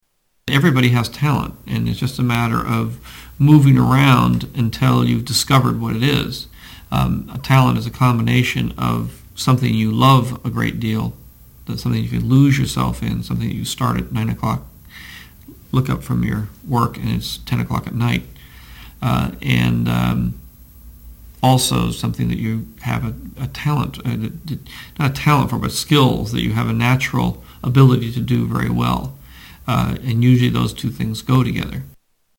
Tags: Media George Lucas audio Interviews George Lucas Star Wars Storywriter